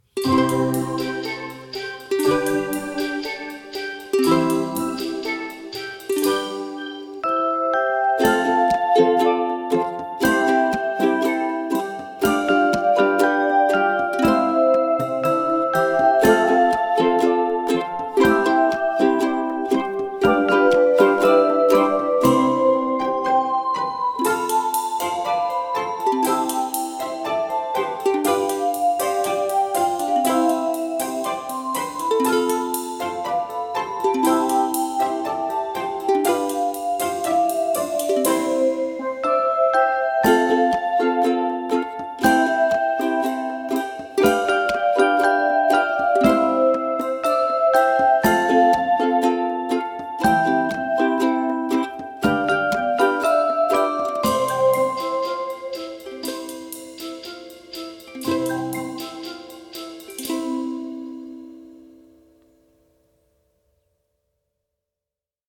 A tune from the Caribbean where the swimming of a little turtle mingles with the gentle rhythm of lapping waves.
The music features steel pans and the accompaniment for chord instruments has chords that are very suitable for ukuleles.
The chorus then returns and the piece ends with steel pans again, with sea sounds getting quieter and with everyone whispering ‘shhhhh…’